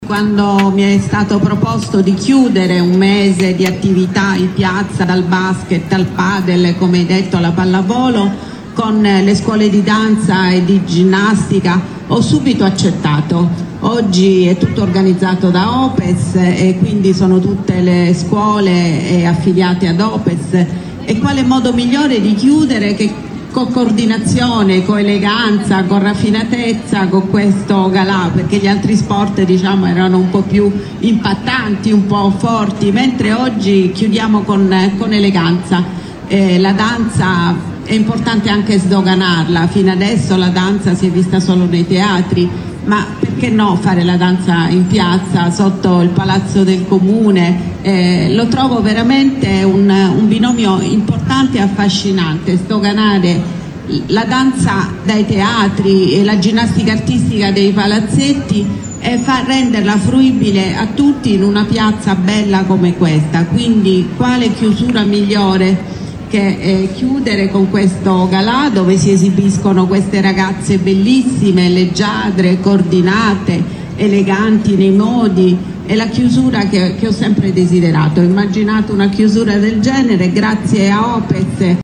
SINDACO-GALA.mp3